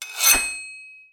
SWORD_24.wav